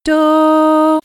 Medium Melody 2: Tonic